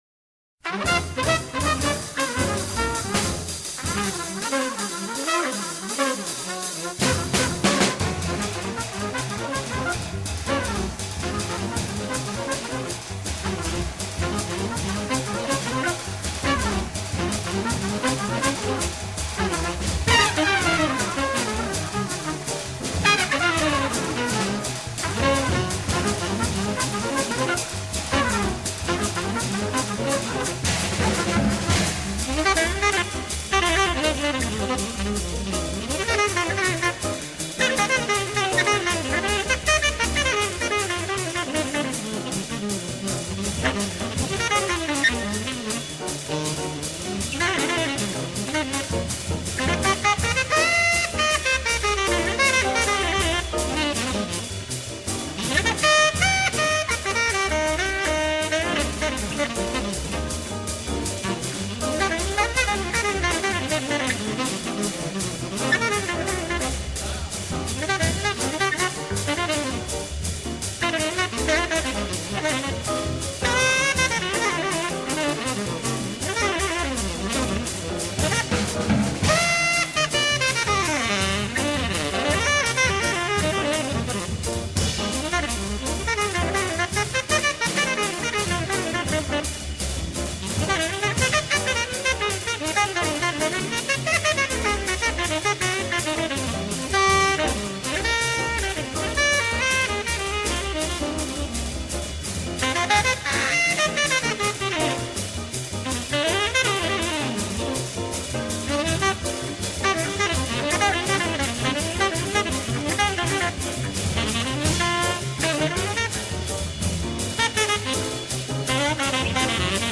Época y 3 características del BEBOP
Rápida y furiosa.
Con complejas melodías y ritmos. Armonías disonantes e imprivisaciones. Para pequeñas bandas y locales más íntimos.